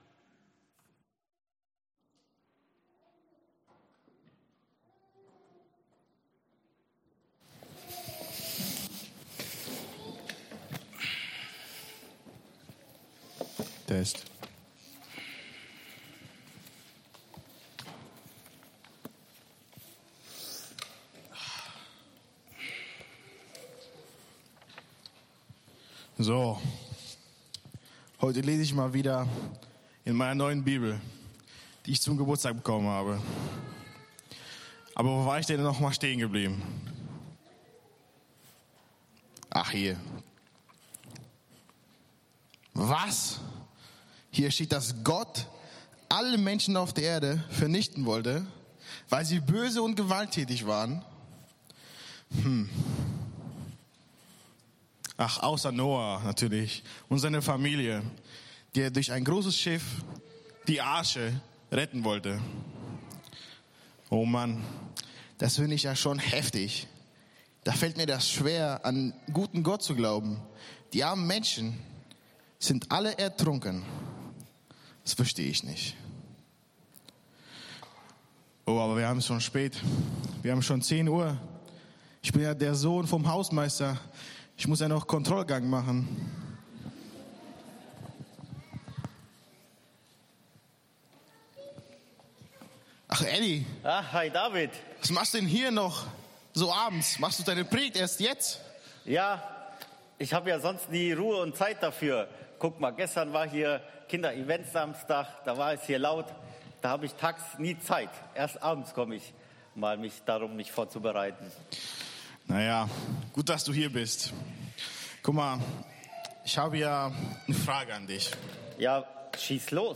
EFG-Haiger Predigt-Podcast